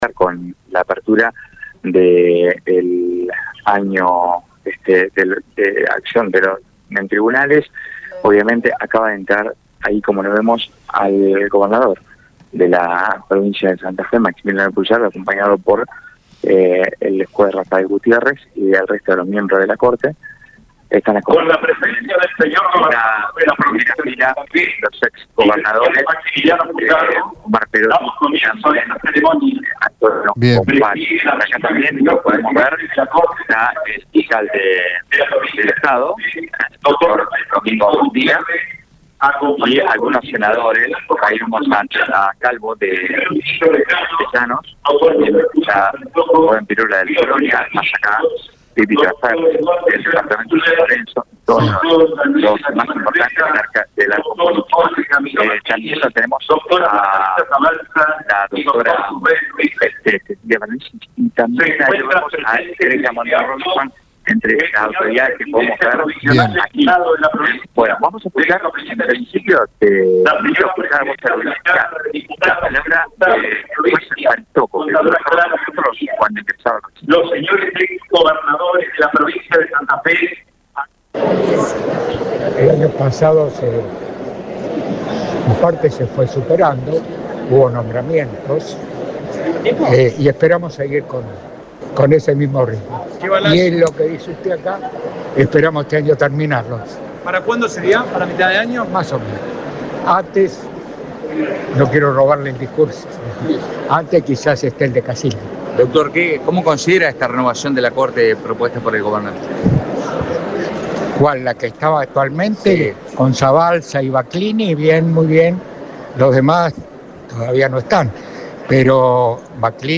El ministro del máximo tribunal provincial habló durante la apertura del año judicial y aseguró que deja el cargo conforme con los cambios impulsados en la Justicia durante las últimas décadas.